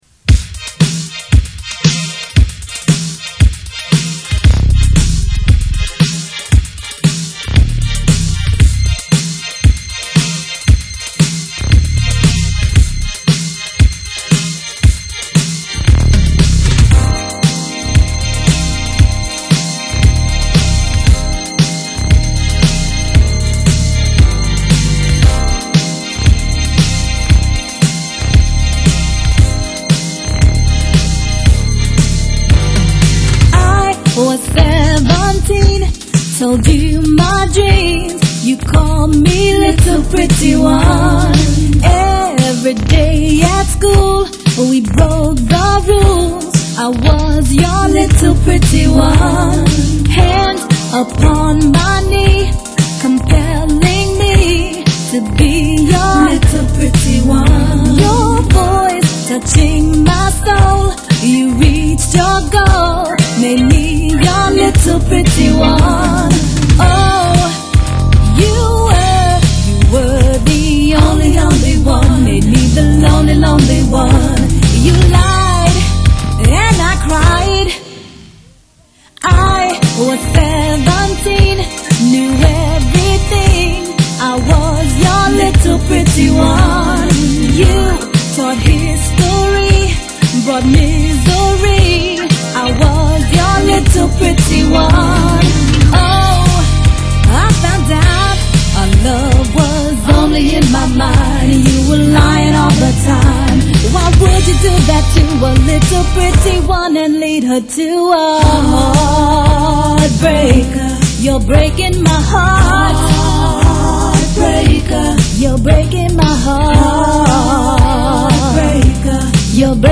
brassy alto
a much sweeter and rounder tone
make the kick drum the loudest thing on the track
I only had the wimpier 707 drum machine